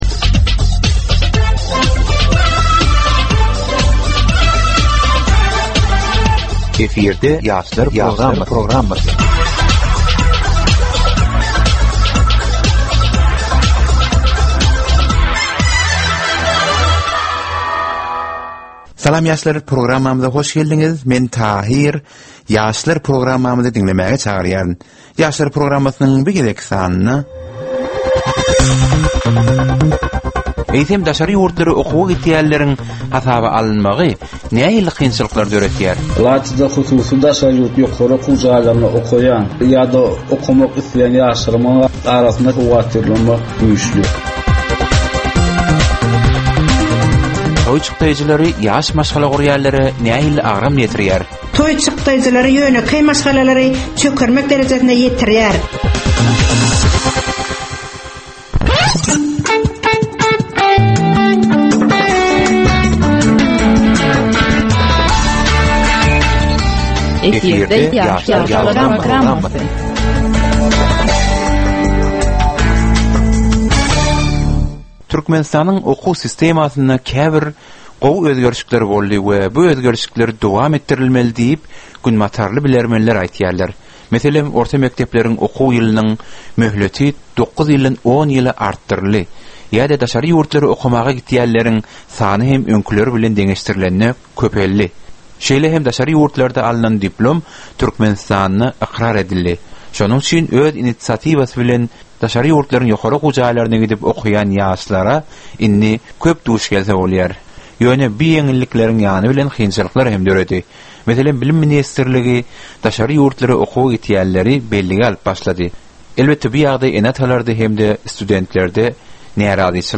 Geplesigin dowmynda aýdym-sazlar hem esitdirilýär.